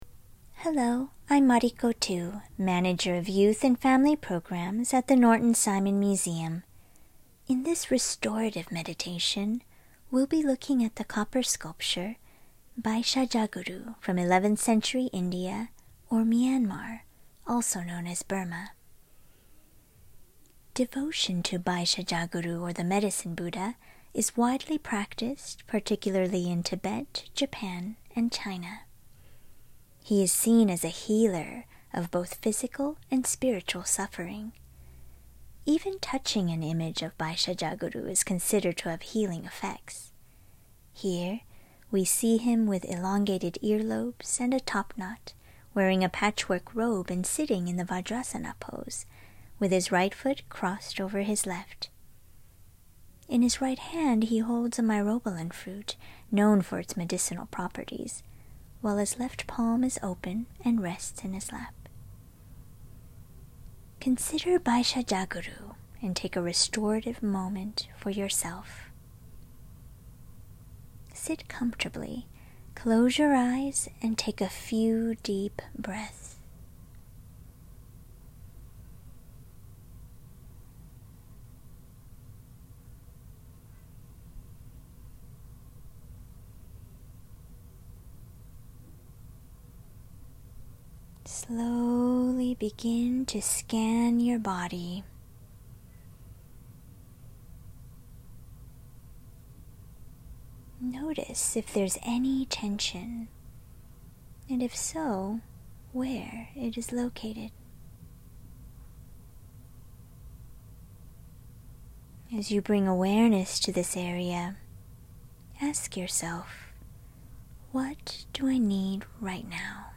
Restorative Meditation
restorative-meditation.mp3